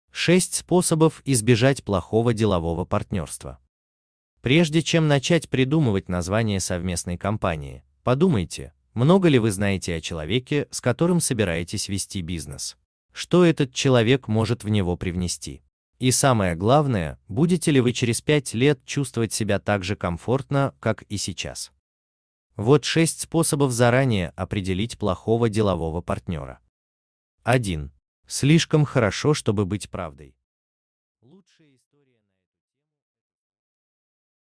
Аудиокнига Как избежать плохого делового партнерства: способы | Библиотека аудиокниг